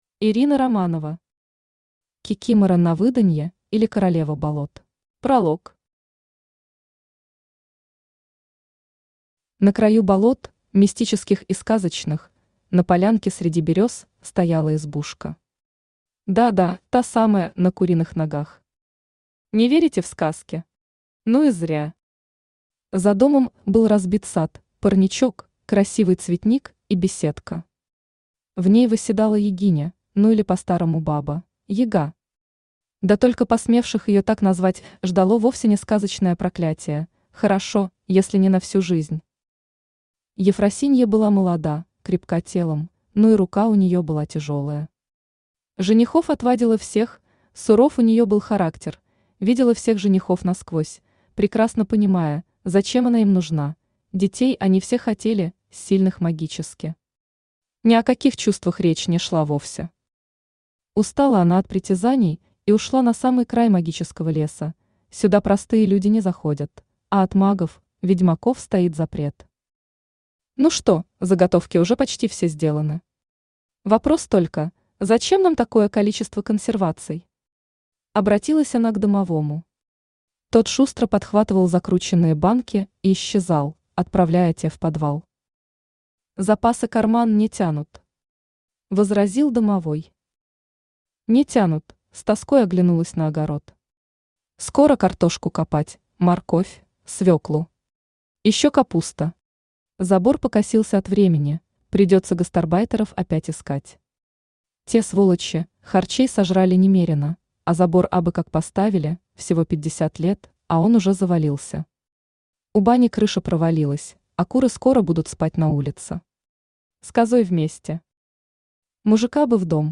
Аудиокнига Кикимора на выданье, или Королева болот | Библиотека аудиокниг
Aудиокнига Кикимора на выданье, или Королева болот Автор Ирина Романова Читает аудиокнигу Авточтец ЛитРес.